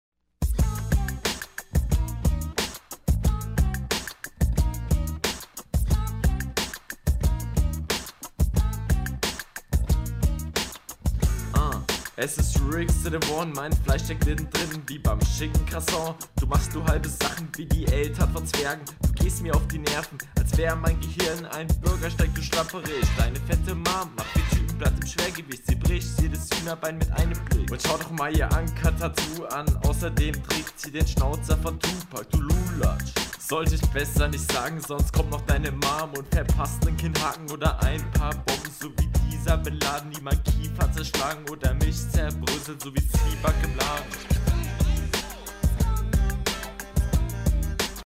Battle Runden